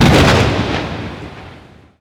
1 channel
THUNDER.WAV